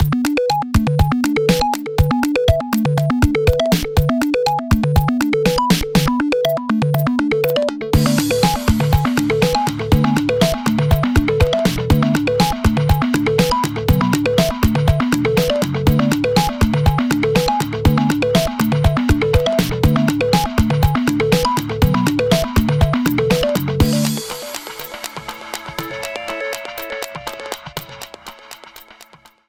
faded out the last two seconds